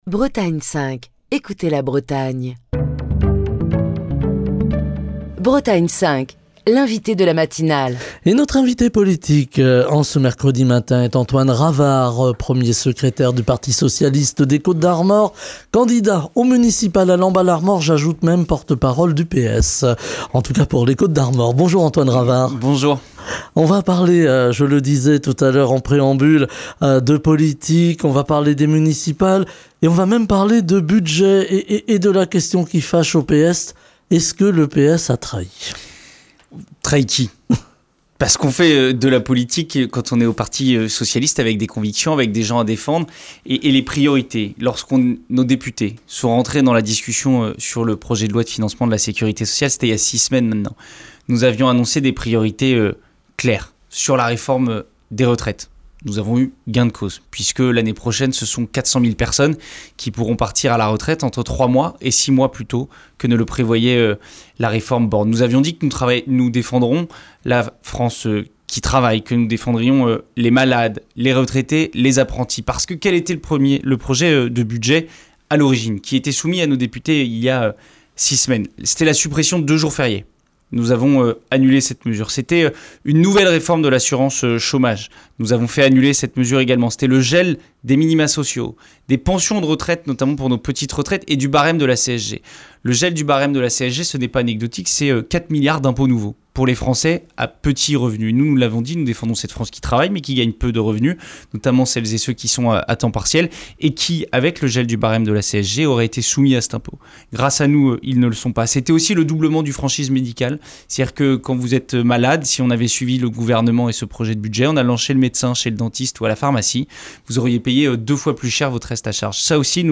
Écouter Télécharger Partager le podcast Facebook Twitter Linkedin Mail L'invité de Bretagne 5 Matin